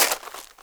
GRAVEL 3.WAV